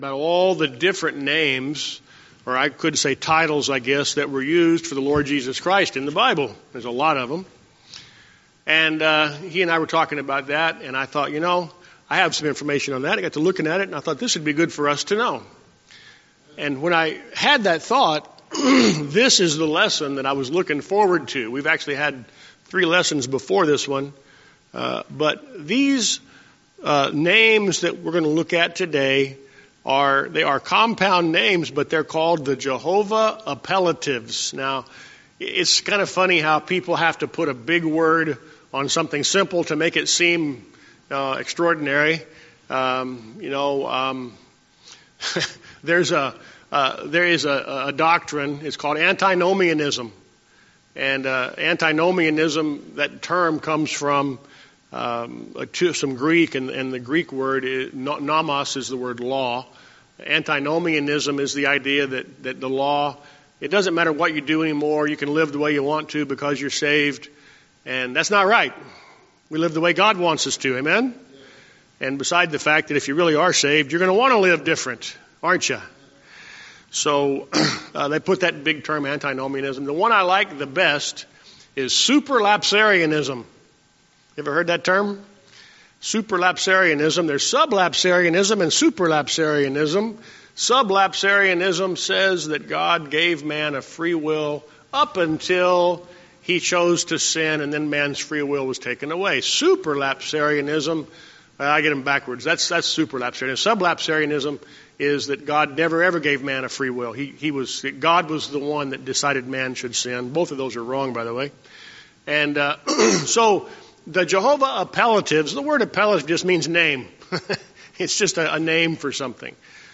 Sunday School Recordings
Sermons